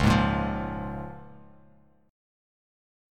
Db6 Chord
Listen to Db6 strummed